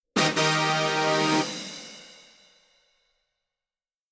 fanfare.mp3